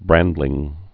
(brăndlĭng)